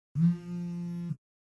Sound Buttons: Sound Buttons View : Phone Vibrating Left
vibrate-left.mp3